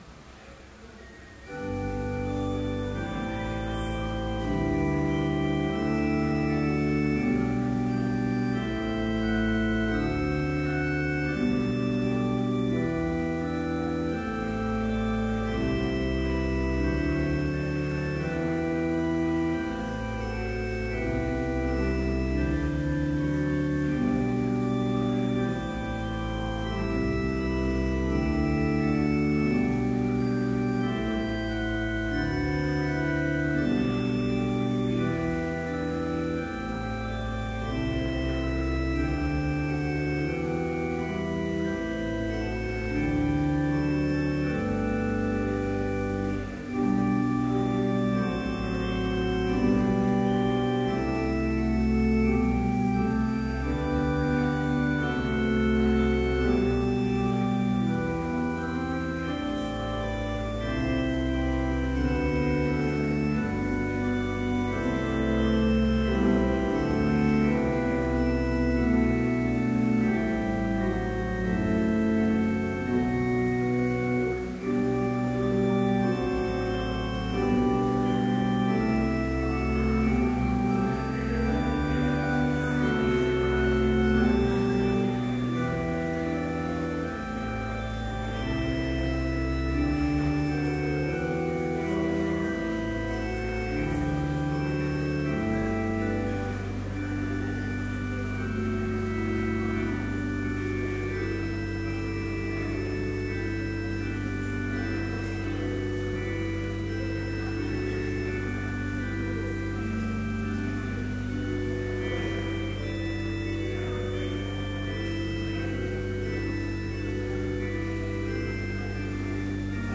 Complete service audio for Chapel - February 5, 2013